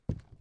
ES_Walk Wood Creaks 8.wav